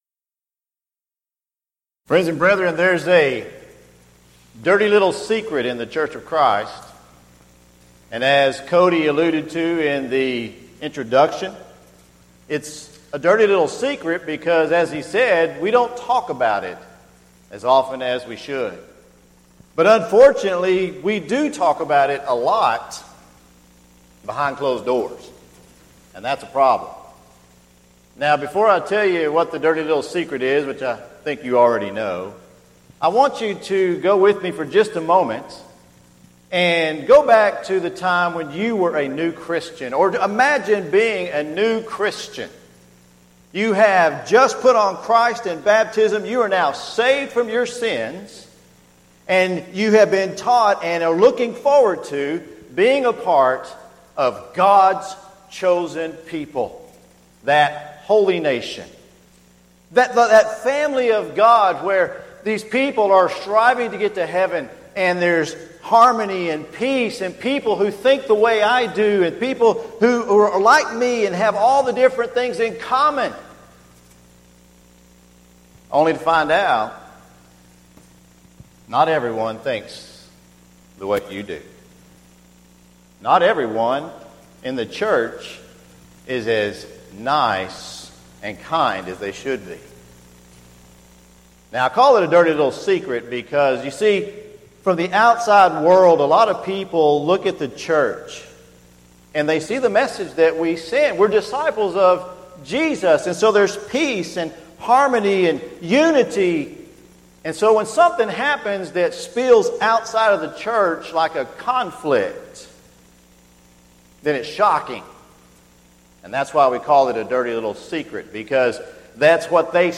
Event: 4th Annual Arise Workshop Theme/Title: Biblical Principles for Congregational Growth
lecture